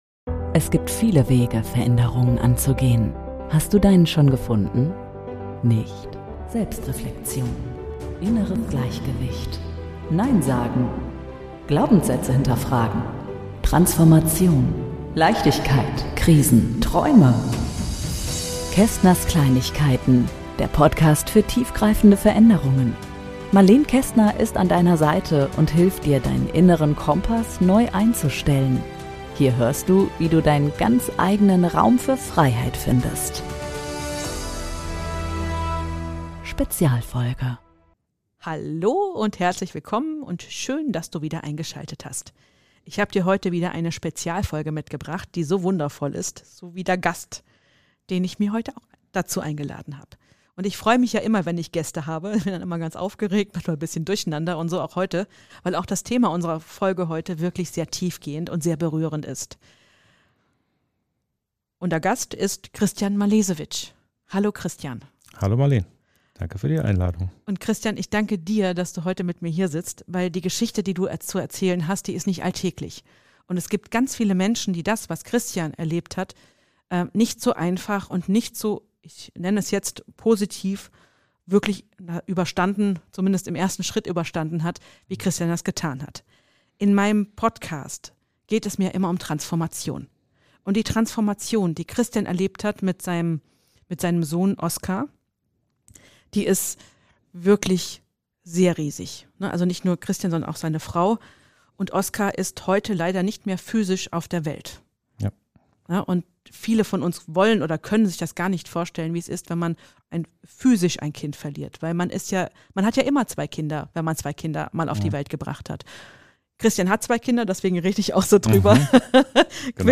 in unserem Gespräch mit mir